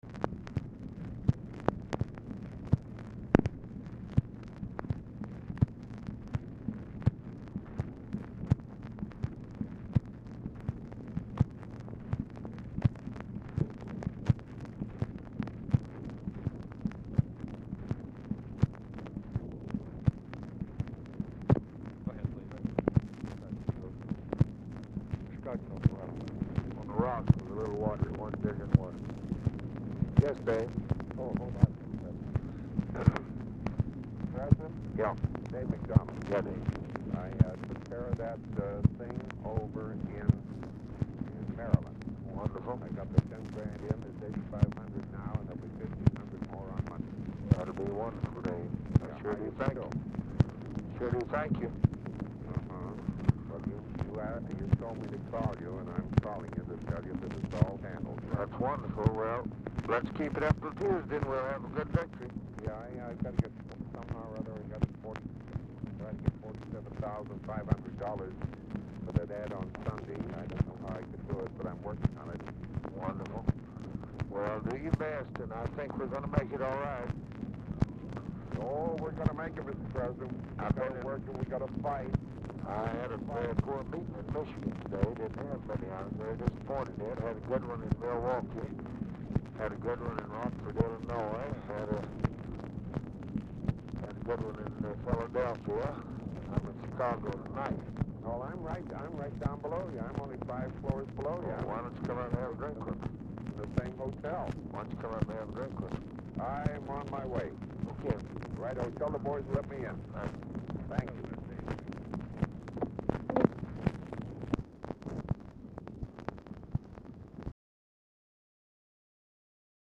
OFFICE CONVERSATION PRECEDES CALL; POOR SOUND QUALITY
Format Dictation belt
Specific Item Type Telephone conversation Subject Elections Labor Lbj Personal Lbj Travel National Politics